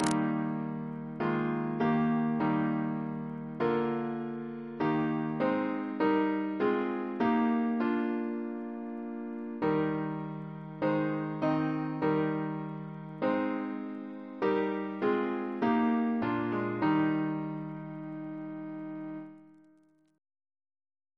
Double chant in E♭ Composer: James Radcliffe (1751-1818) Reference psalters: OCB: 135